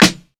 CDK Blend Snare.wav